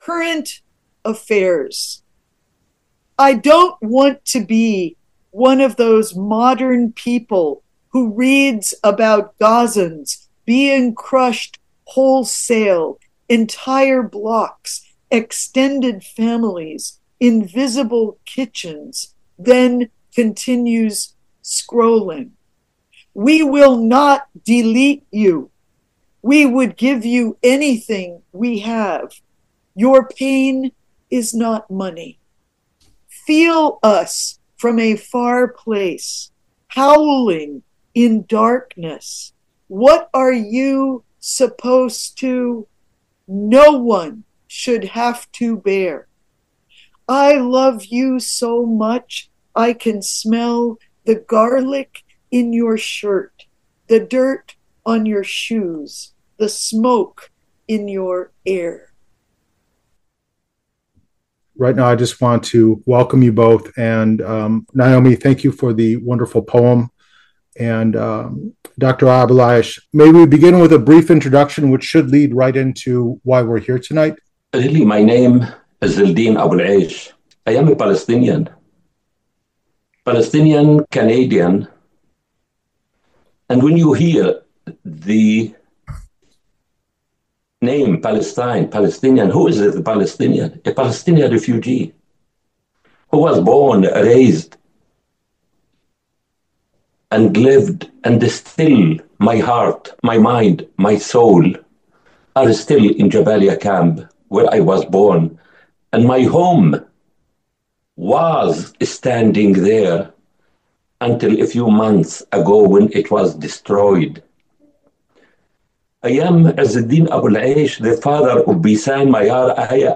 Palestinian Realities in Gaza and The West Bank; Cost, Record, and Directions: A Talk with Naomi Shihab Nye and Five Time Nobel Peace Prize Nominee Dr. Izzeldin Abuelaish